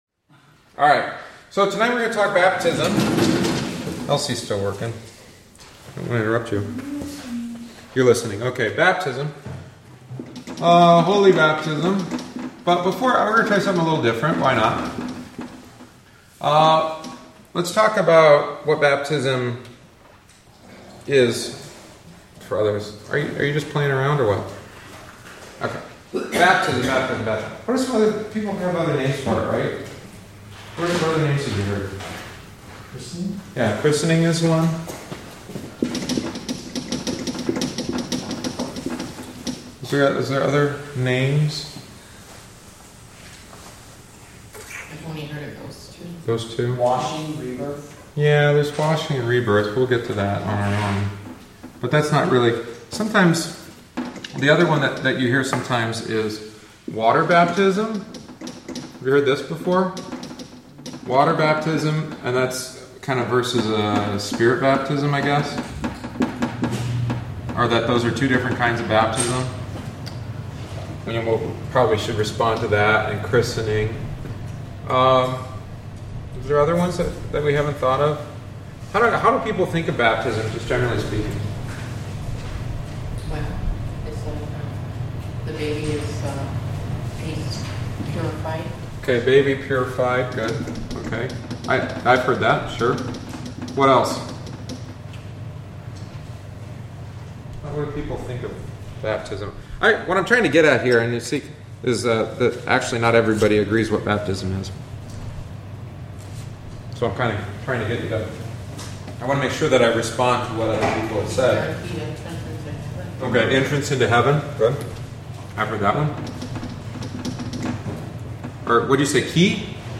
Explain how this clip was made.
Join us for Adult Catechumenate classes following each Wednesday Divine Service. This is offered for those that would like a refresher course on their catechetical instruction and especially for those desiring to join us and confess the Lutheran faith.